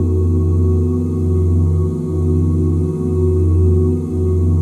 OOHF FLAT5.wav